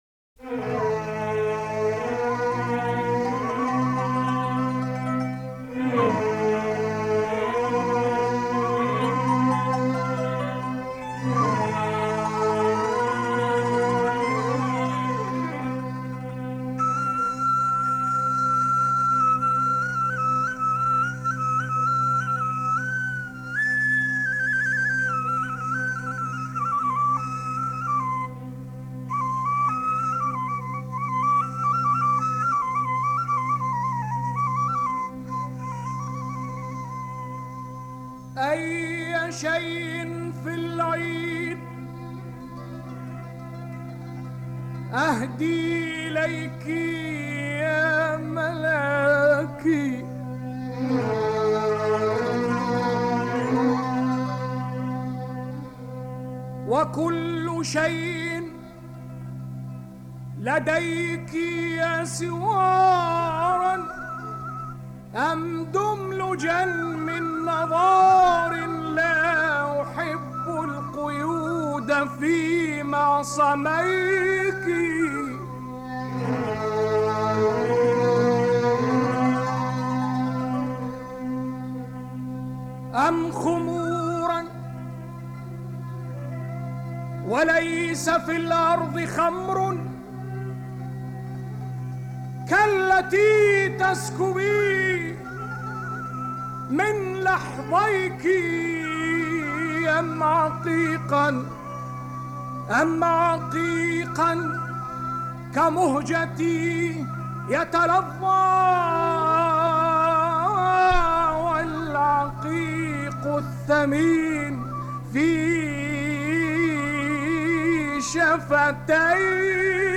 اغنية عربية